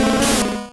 Cri d'Ortide dans Pokémon Rouge et Bleu.